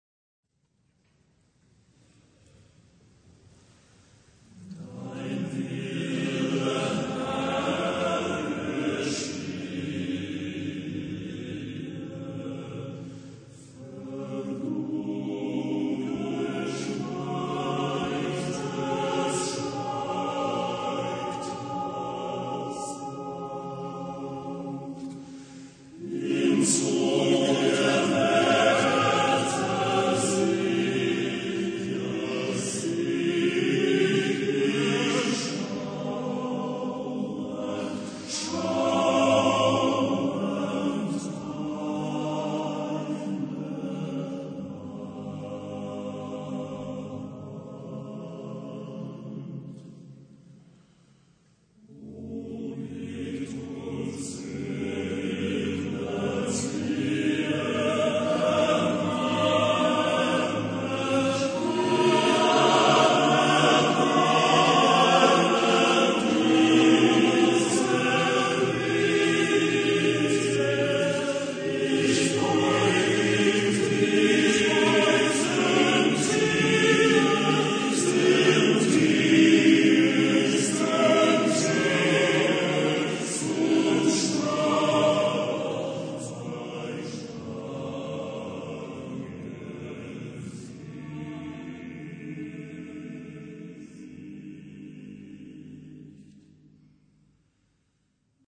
Genre-Style-Forme : Romantique ; Sacré ; Lied
Type de choeur : TTBB  (4 voix égales d'hommes )
Tonalité : do majeur